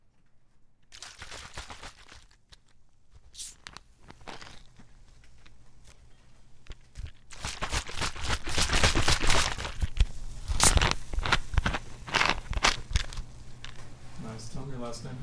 抽象的鼓包 " 气体蒸汽
描述：在给它加气后取出一瓶苏打水机
Tag: 空气 压力 蒸汽 煤气 打击